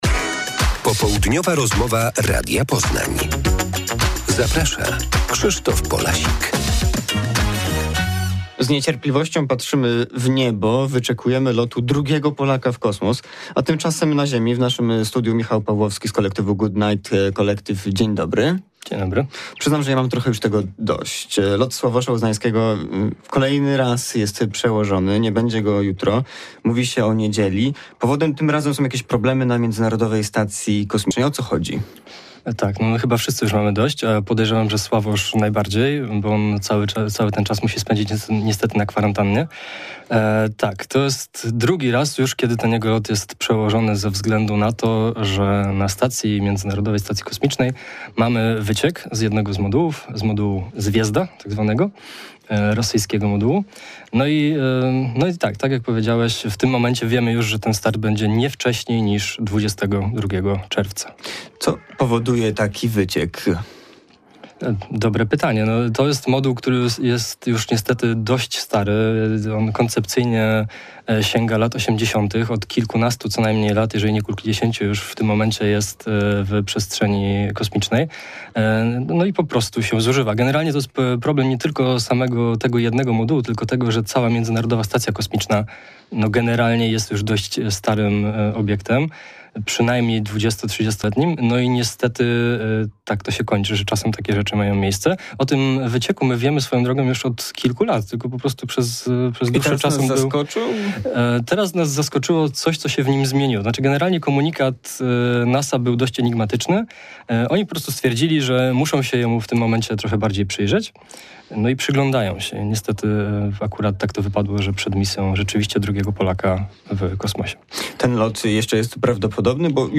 Autor: , Tytuł: 18 06 Popołdniowa rozmowa